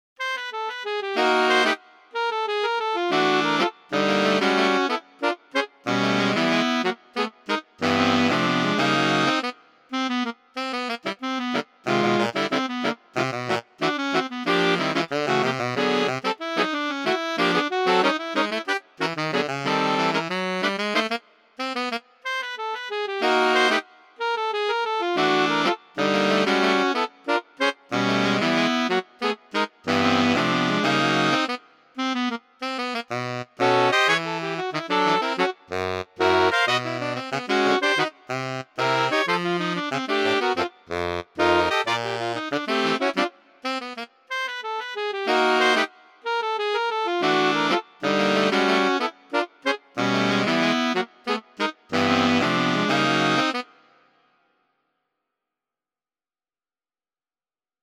• Short Pieces for Saxophone Quartet
We managed, but the effect was very different from the precise computer-rendered versions here.